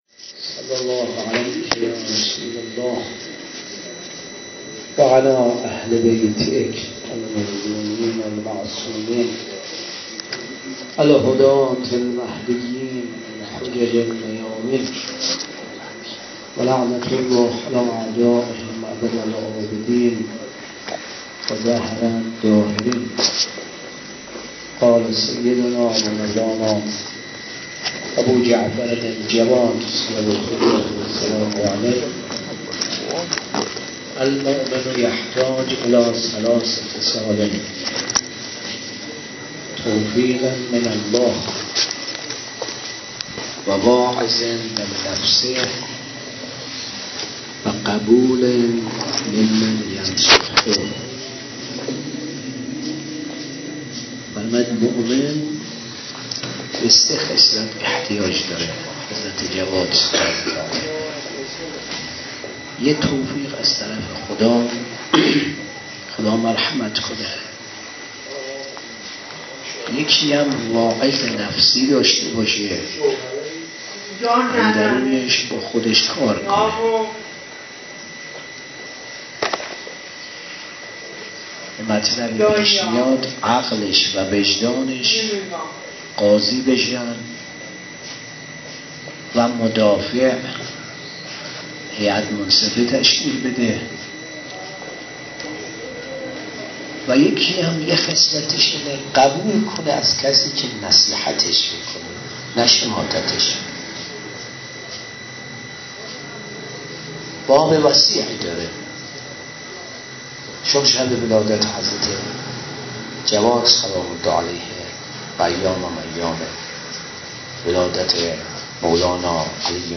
ولادت امام جواد (ع) 96 - هیئت مکتب الحسین - سخنرانی